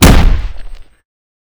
Punch_Hit_15.wav